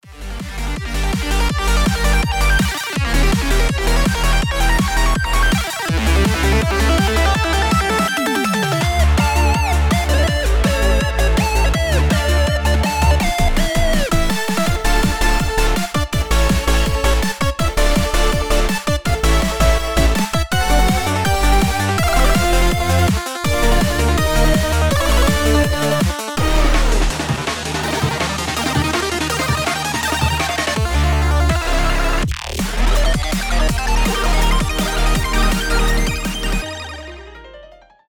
complextro
без слов